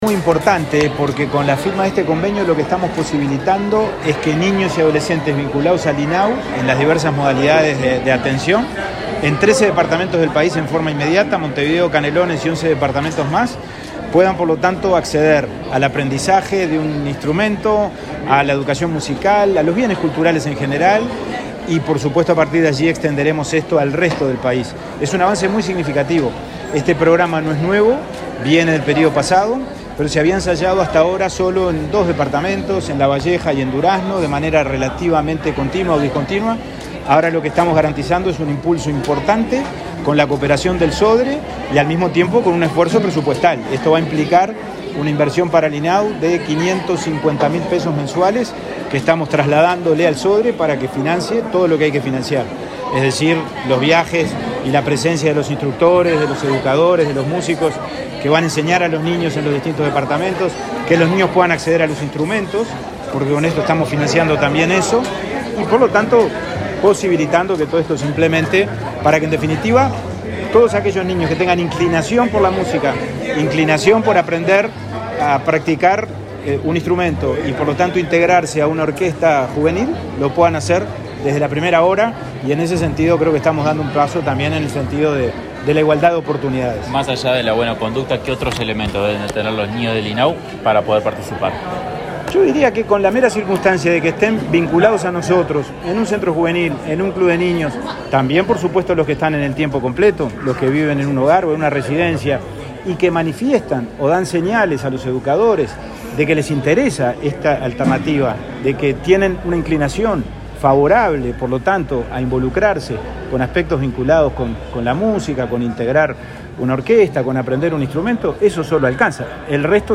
Declaraciones del presidente del INAU, Pablo Abdala
Declaraciones del presidente del INAU, Pablo Abdala 03/01/2022 Compartir Facebook X Copiar enlace WhatsApp LinkedIn El presidente del Instituto del Niño y el Adolescente del Uruguay (INAU), Pablo Abdala, dialogó con la prensa luego de la firma de un acuerdo con autoridades del Sodre, en el marco del programa Un Niño, Un Instrumento, para que niños y adolescentes vinculados al INAU reciban educación musical y accedan a bienes culturales.